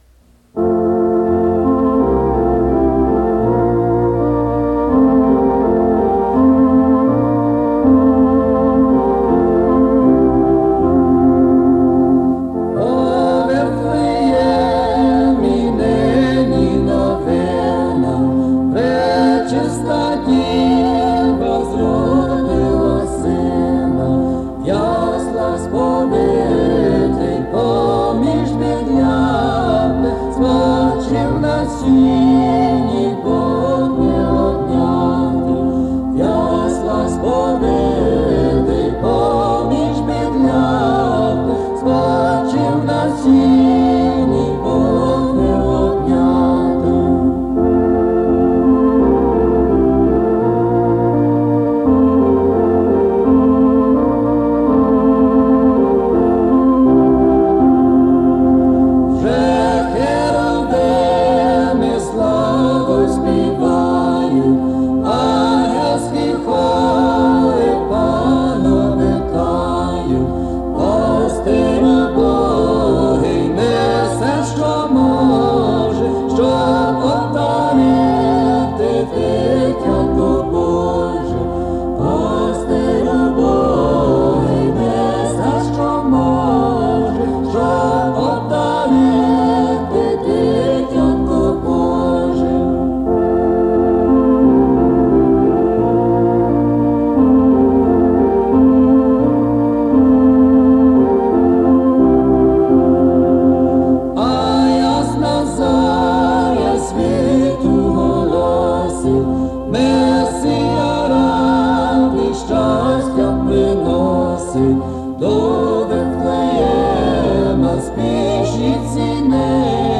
Traditional Ukrainian Carols